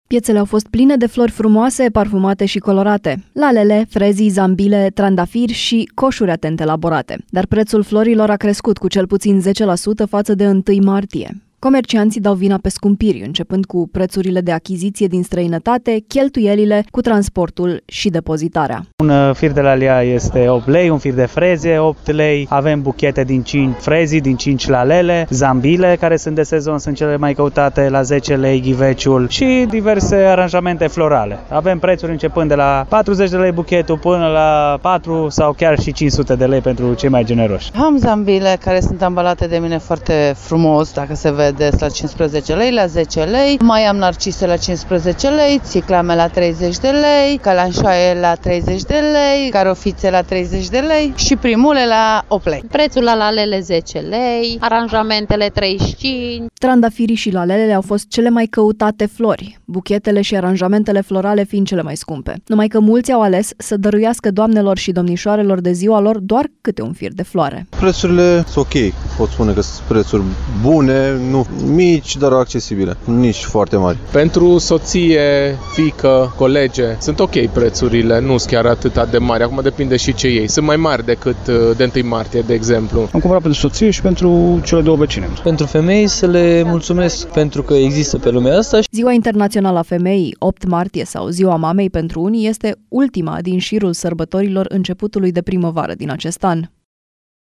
Avem prețuri începând de la 40 lei buchetul până la 400 sau chiar și 500 de lei pentru cei mai generoși”, spune un comerciant.
Am zambile care sunt ambalate de mine foarte frumos, dacă se vede, la 15 lei, la 10 lei, mai am narcise la 15 lei, garofițe la 30 lei și primule la 8 lei”, spune o vânzătoare de flori.
Nici foarte mari”, spune un cumpărător.
Sunt mai mari decât de 1 martie, de exemplu”, constată un alt cumpărător.